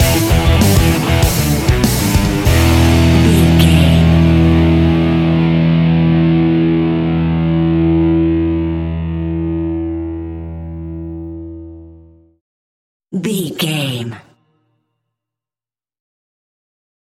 Ionian/Major
energetic
driving
heavy
aggressive
electric guitar
bass guitar
drums
hard rock
heavy metal
distortion
instrumentals
heavy drums
distorted guitars
hammond organ